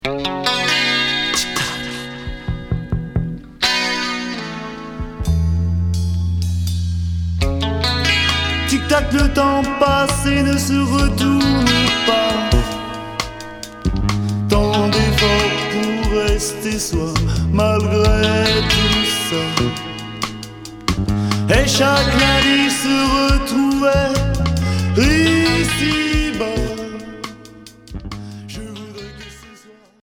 Rock new wave